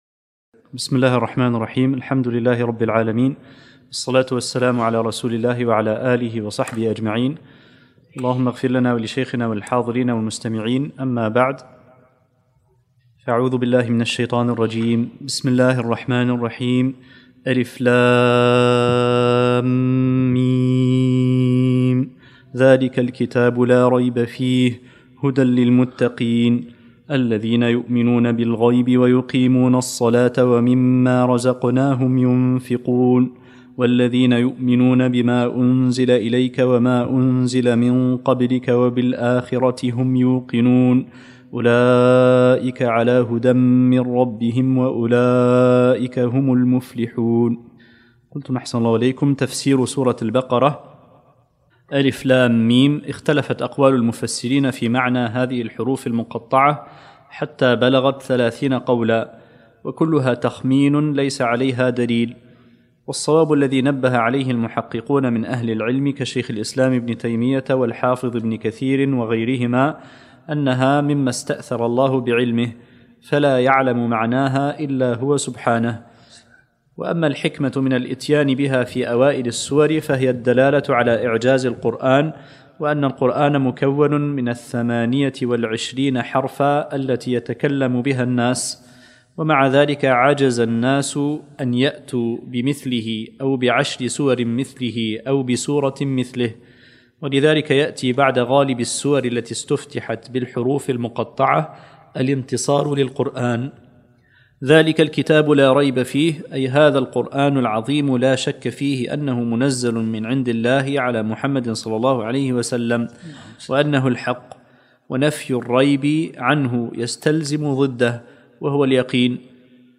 الدرس الأول من سورة البقرة